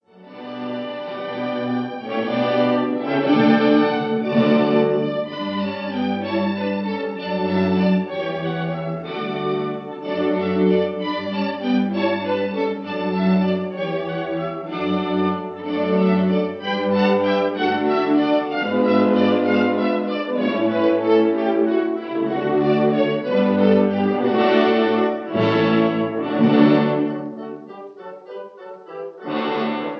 An historic 1936 recording